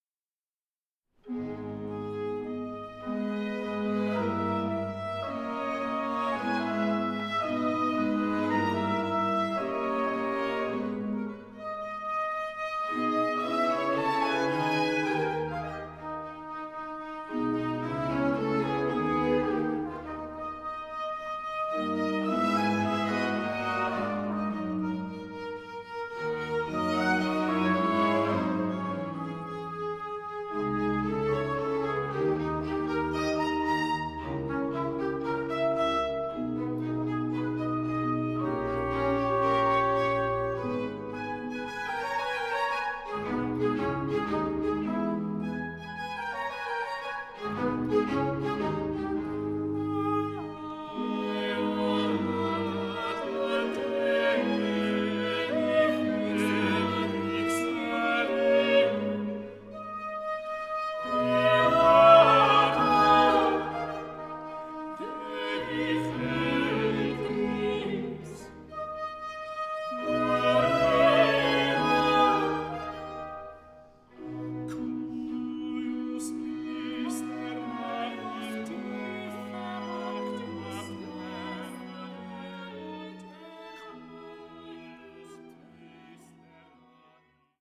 una de las obras de referencia del Barroco musical español.